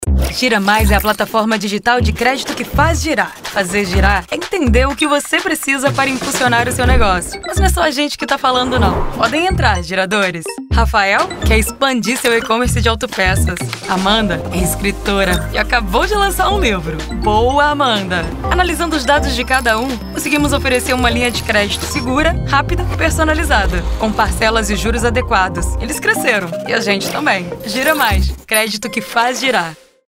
Voz com um leve “rouquinho” pra deixar seu anuncio com um toque único, trazendo mais sofisticação e visibilidade no mercado publicitário.